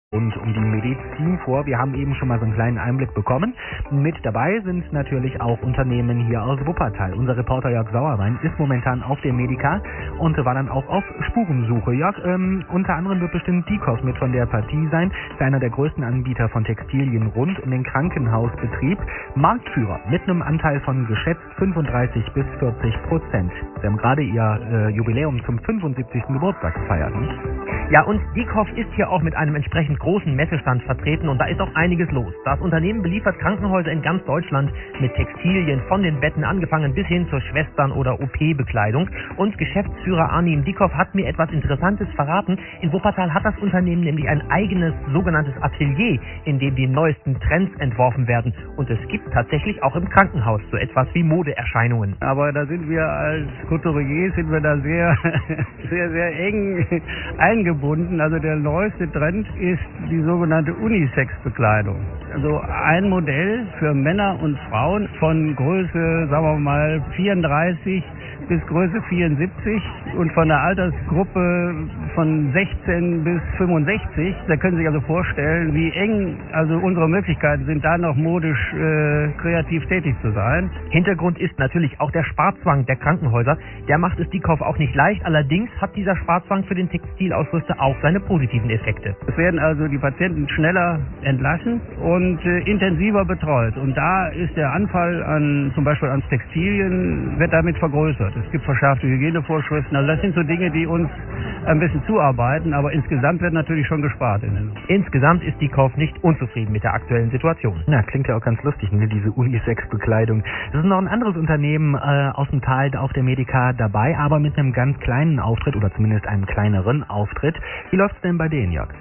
Im Interview: Radio-Wuppertal auf der Medica